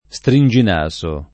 [ S trin J in #S o ]